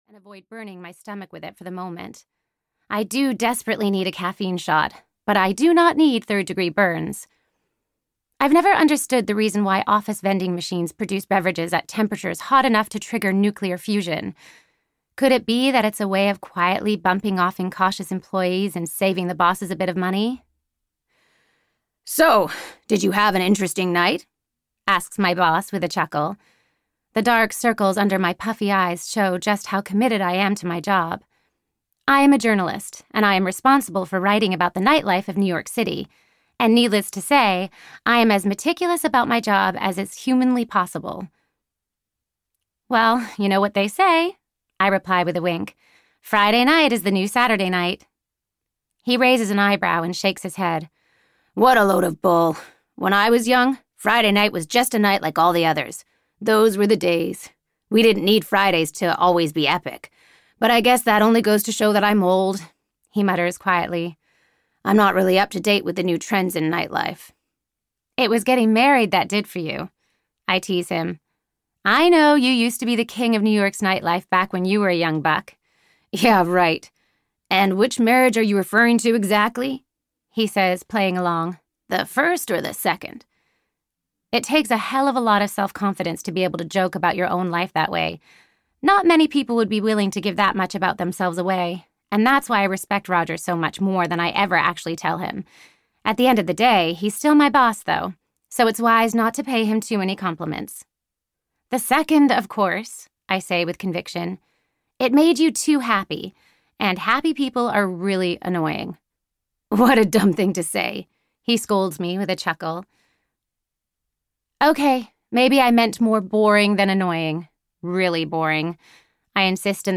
What's Love Got to Do with It? (EN) audiokniha
Ukázka z knihy